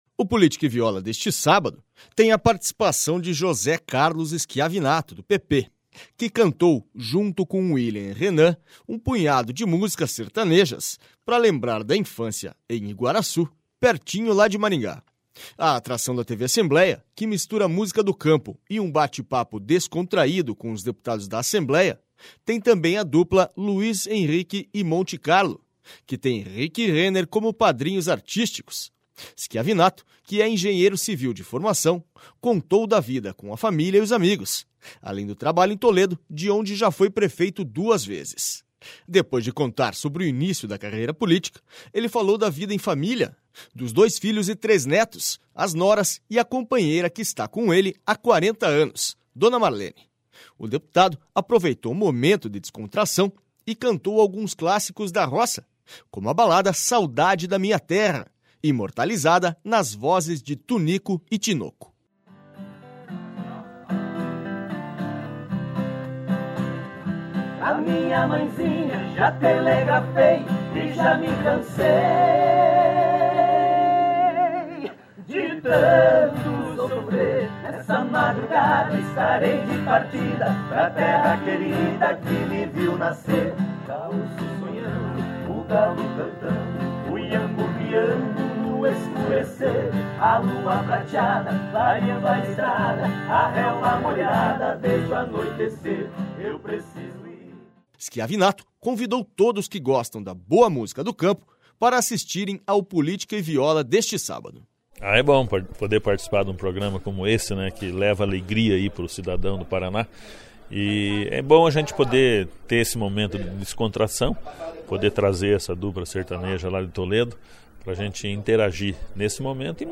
Política e Viola de sábado tem bate papo descontraído com José Carlos Schiavinato
um punhado de músicas sertanejas